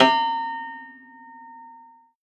53w-pno08-A3.wav